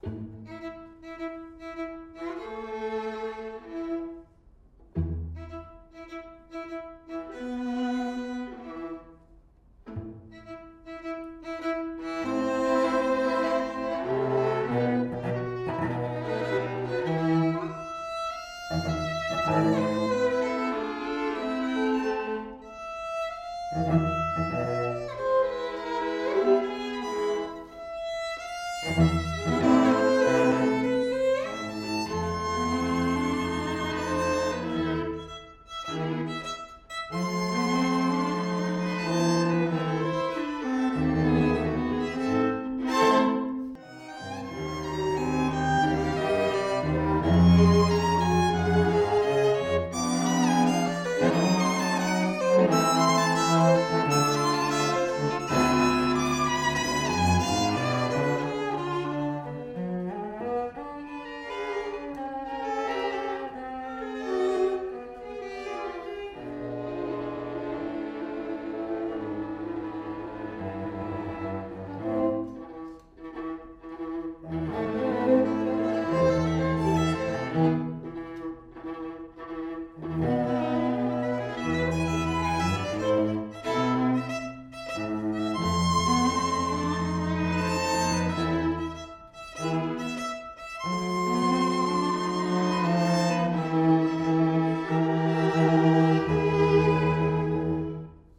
Soundbite 3rd Movt
For 2 Violins, 2 Violas and Cello